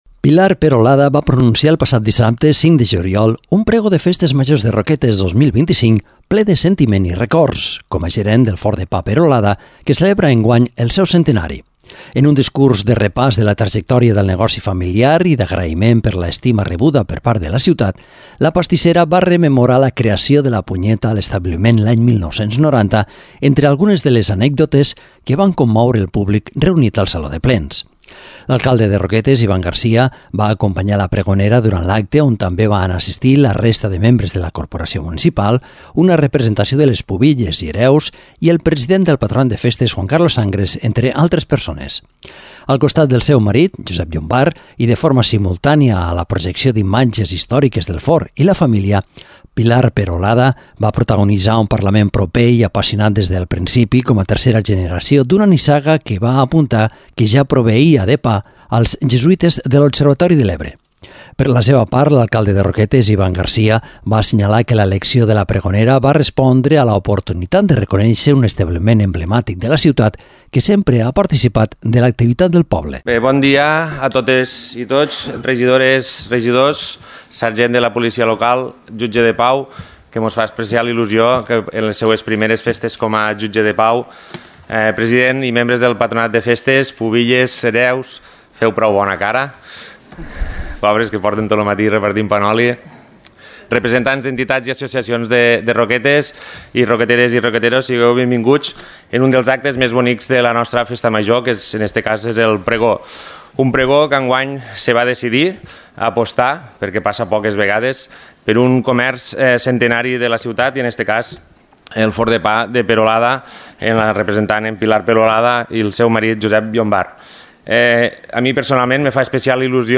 Un discurs entranyable, carregat d’admiració a parts iguals per l’ofici i les arrels.